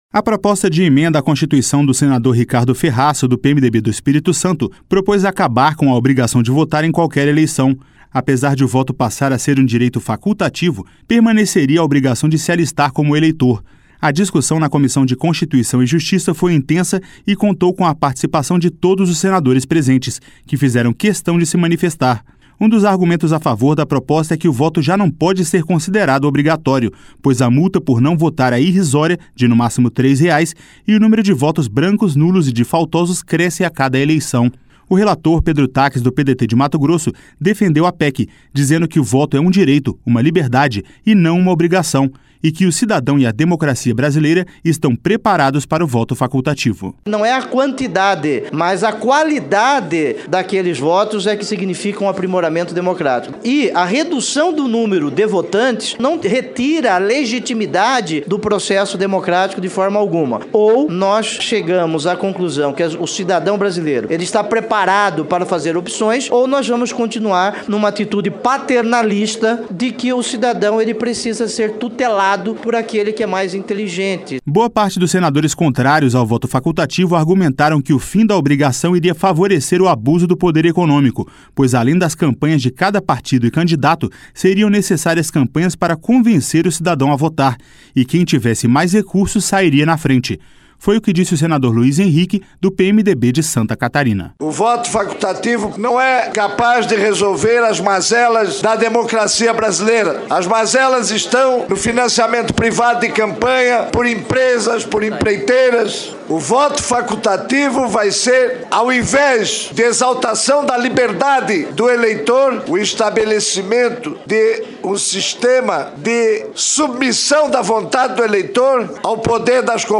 O relator, Pedro Taques, do PDT de Mato Grosso, defendeu a PEC, dizendo que o voto é um direito, uma liberdade, e não uma obrigação.